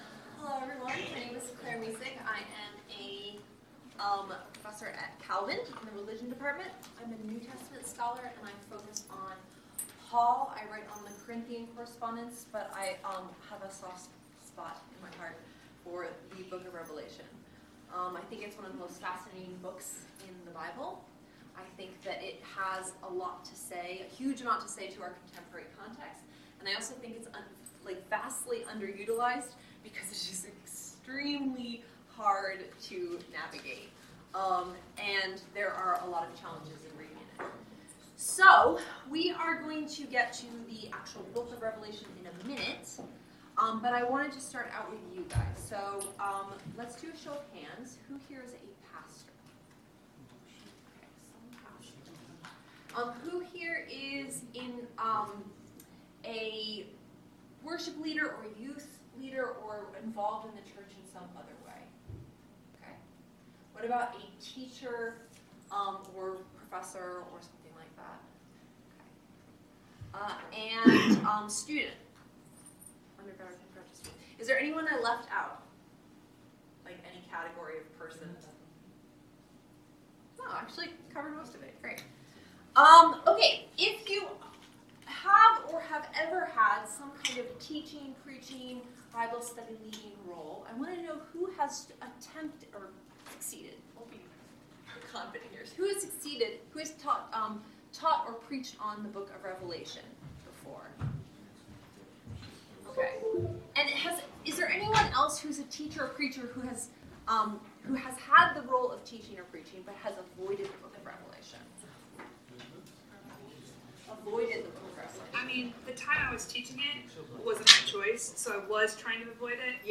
Details 2020 Calvin Symposium on Worship | Workshop Of all the books of the New Testament, Revelation is perhaps the most intimidating.